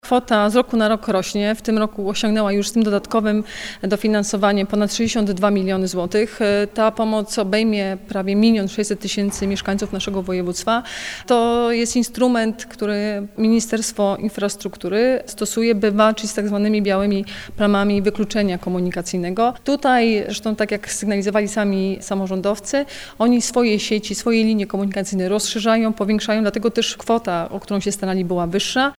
To bardzo dobra wiadomość, mówi Wojewoda Dolnośląska Anna Żabska.